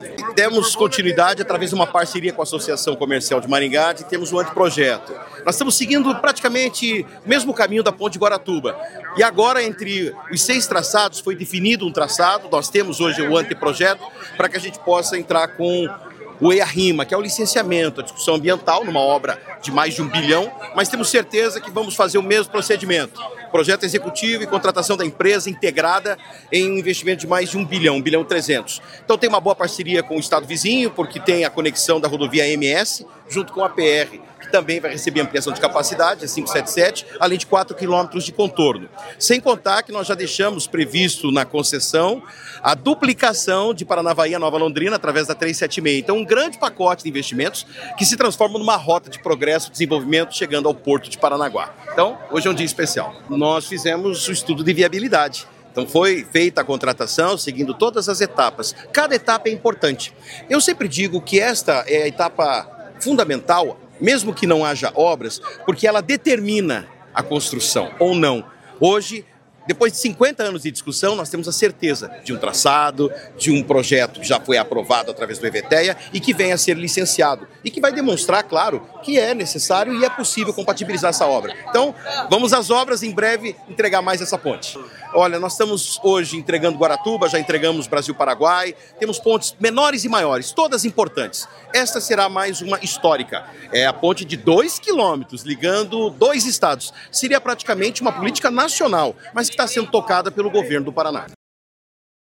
Sonora do secretário de Infraestrutura e Logística, Sandro Alex, sobre o anteprojeto da ponte que vai conectar Paraná e Mato Grosso do Sul